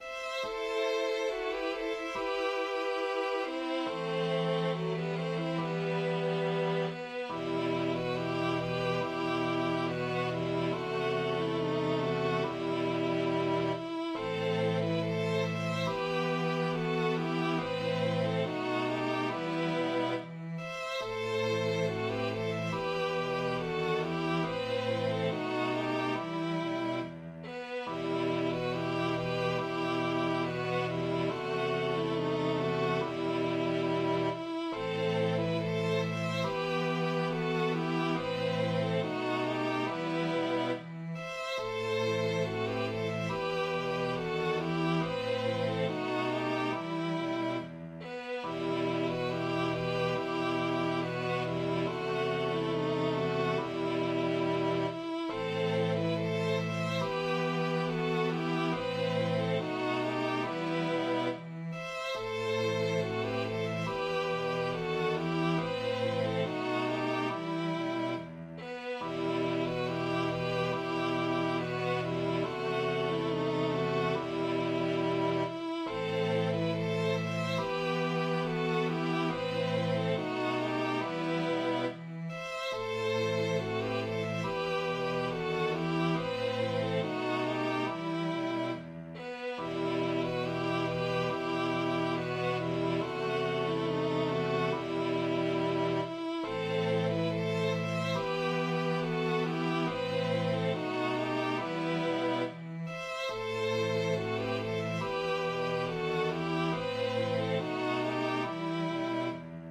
violin
Ab Sommer 994 BF in Garetien populäre Ballade über den Zwist von Jorana von Desmerkuppe und Lutisana vom Berg über einen hübschen Höfling.